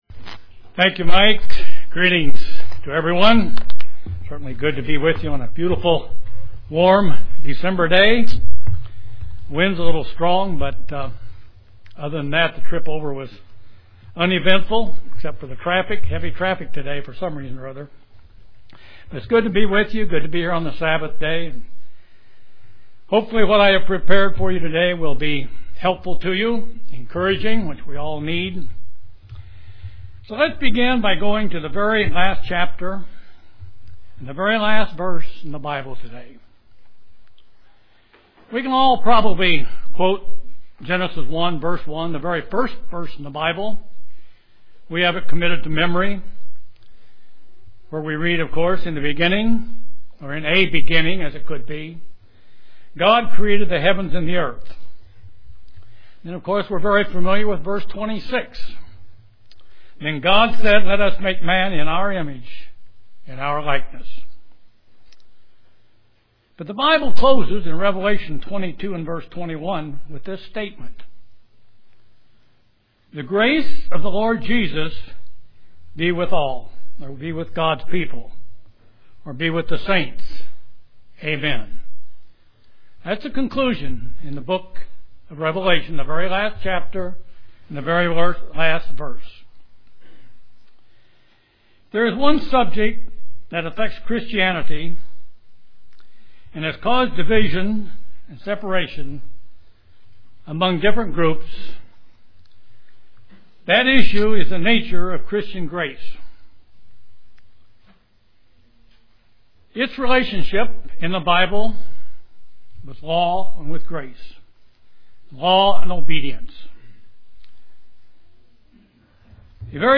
This sermon explains the scriptual concepts surrounding the question of law and grace, and how they work together.